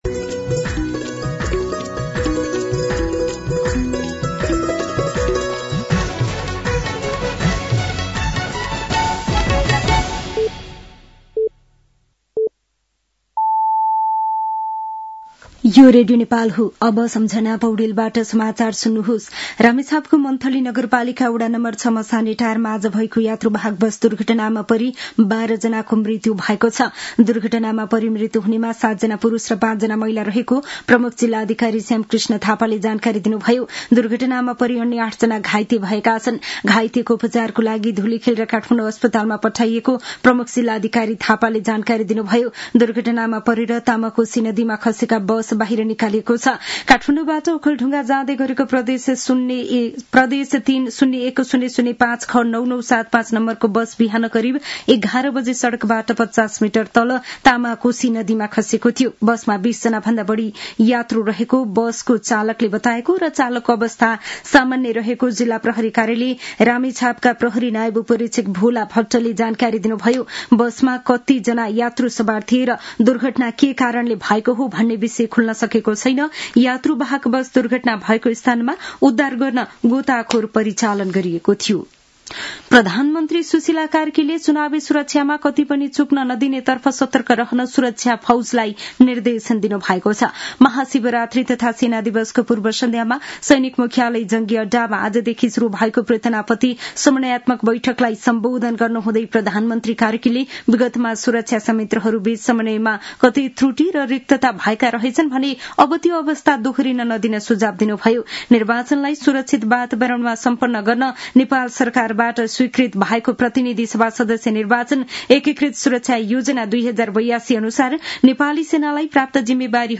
साँझ ५ बजेको नेपाली समाचार : २७ माघ , २०८२
5-pm-news-10-27.mp3